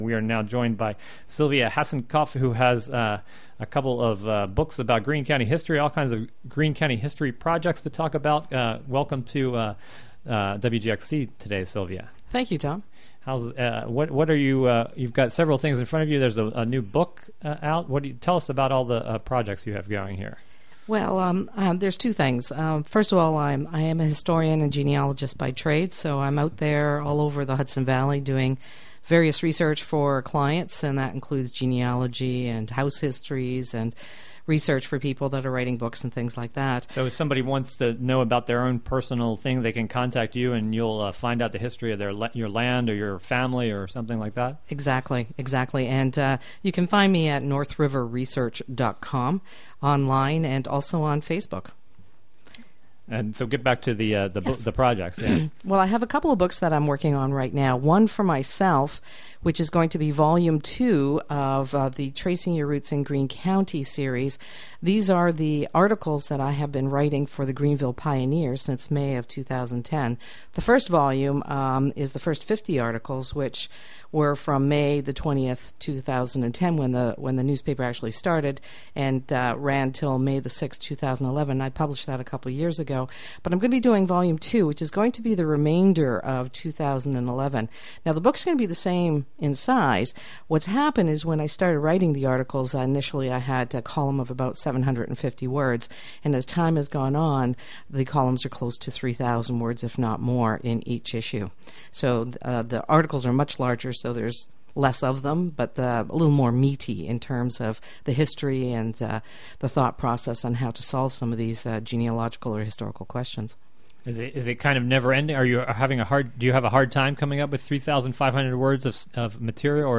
10am Local interviews, local news, regional events, mus...